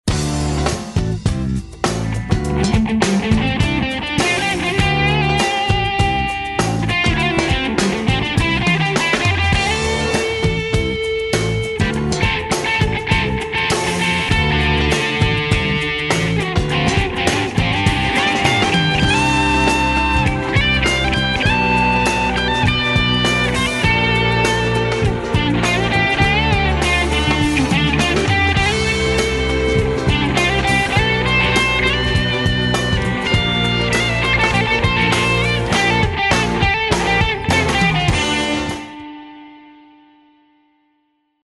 Jam tracks -- nahrajte si vlastne gitarove stopy a zverejnite!
Druhá ukážka je už iba s jednou stopou, zahral som to radšej ležérnejšie, lebo na rýchle sóla nemám ešte techniku :-)